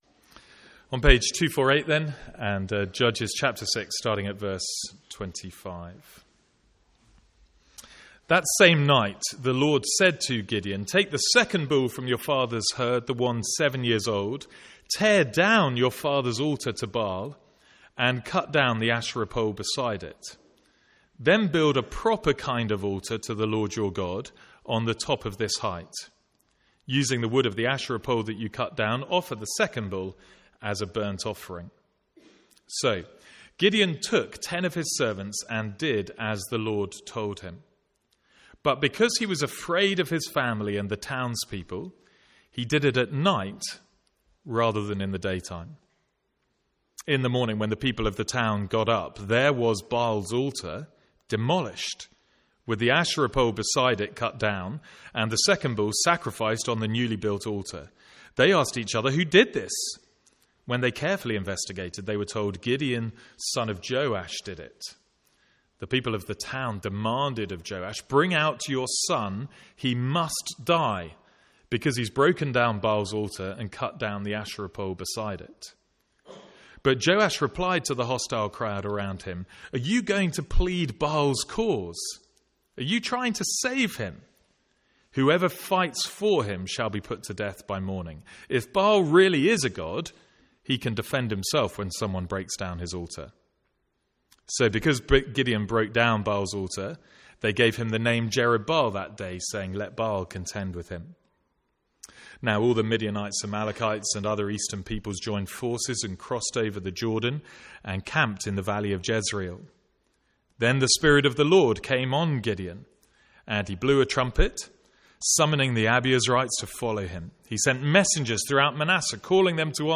From the Sunday morning series in Judges.
Sermon Notes